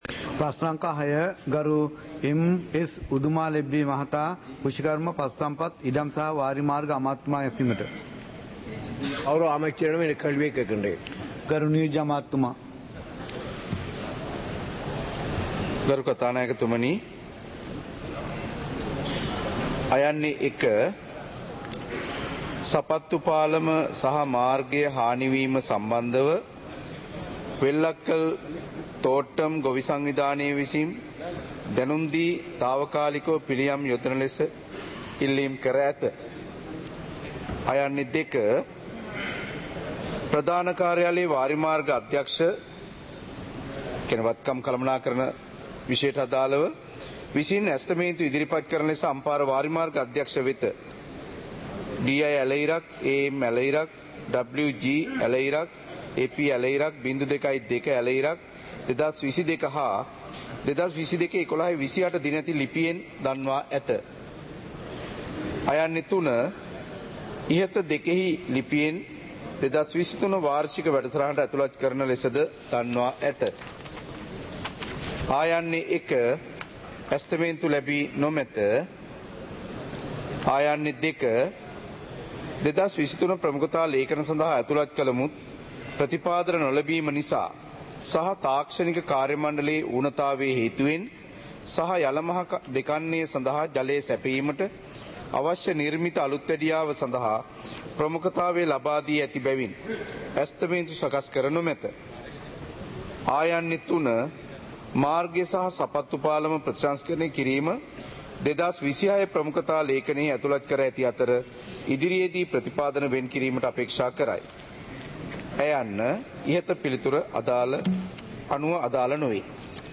இலங்கை பாராளுமன்றம் - சபை நடவடிக்கைமுறை (2026-03-03)